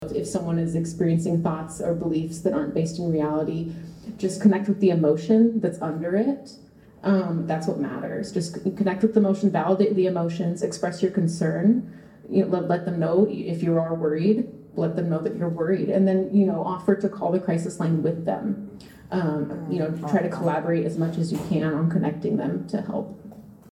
It was an evening of thought-provoking conversation and heart-felt storytelling at Garlington Health Center.